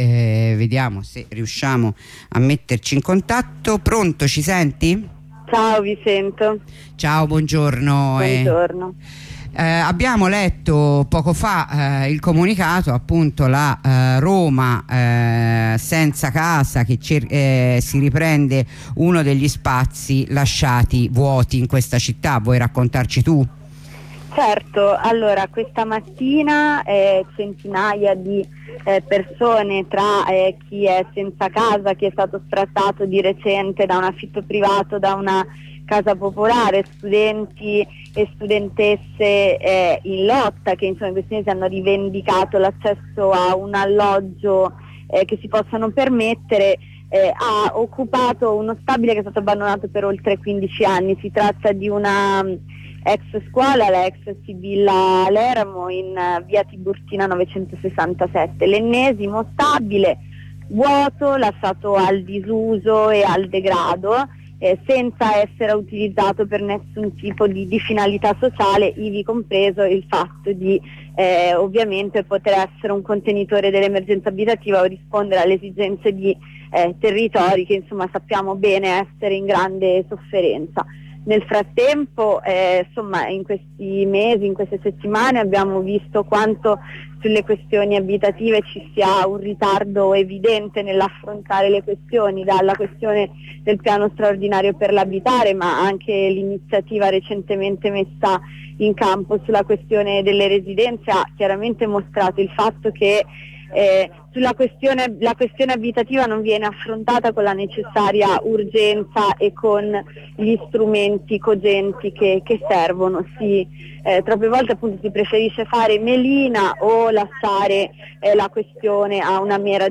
Corrispondenza con una compagna di BPM